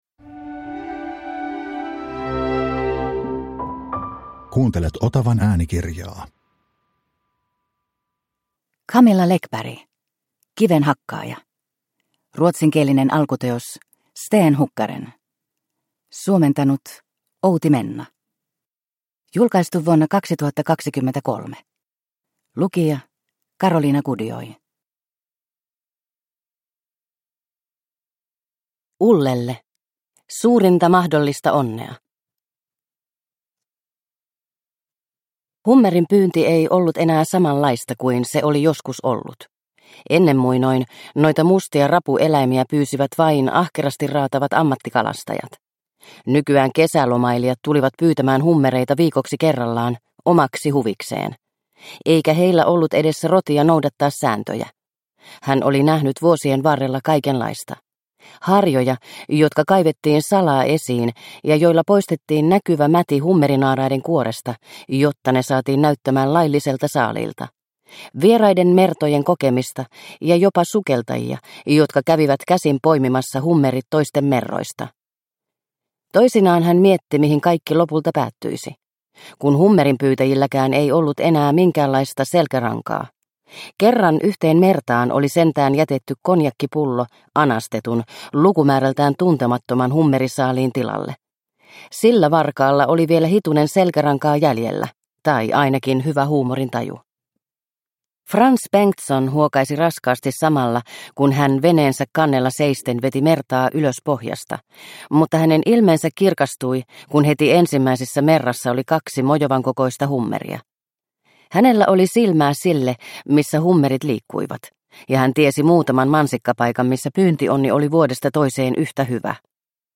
Kivenhakkaaja – Ljudbok – Laddas ner